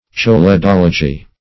Search Result for " choledology" : The Collaborative International Dictionary of English v.0.48: Choledology \Chol`e*dol"o*gy\, n. [Gr. cholh` bile + -logy.